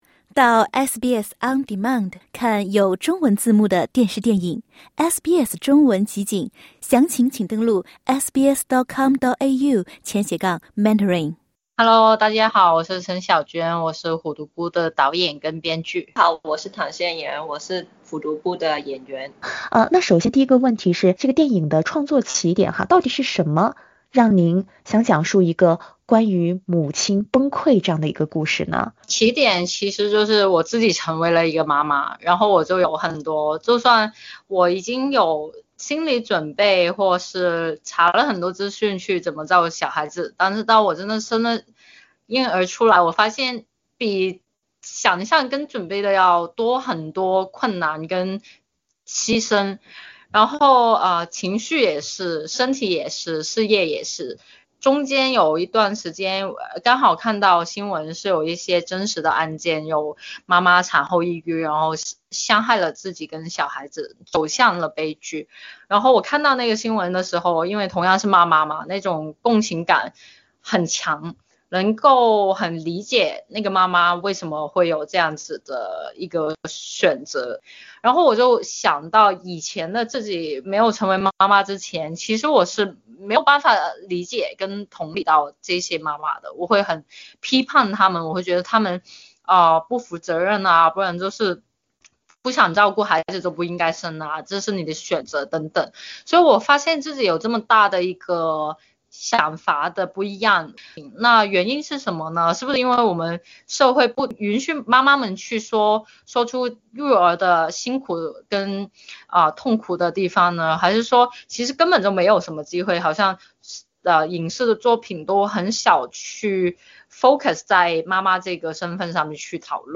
请点击收听采访： LISTEN TO 电影《虎毒不》和“母职惩罚”：成为妈妈后，女性就必须伟大吗？